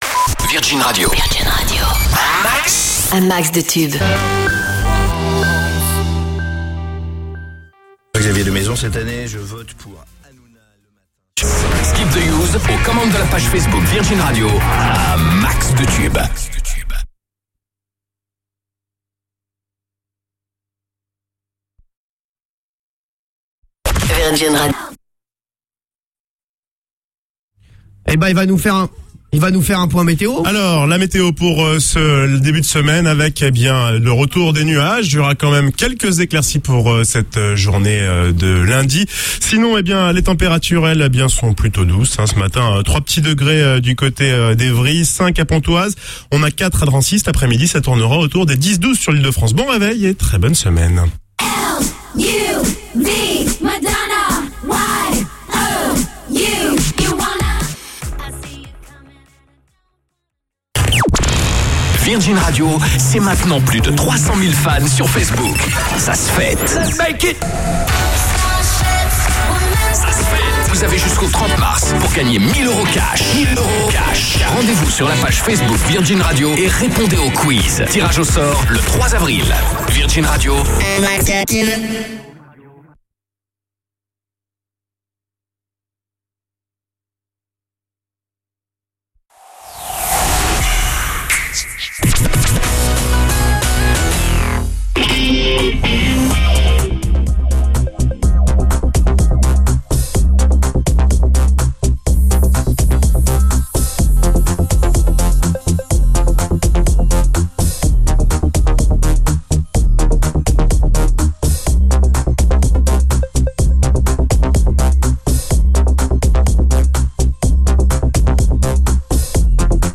Bonus : A la fin des écrans pubs, ça devient le bordel (virgule avant la fin du disque, blanc, ou encore début de jingle coupé..)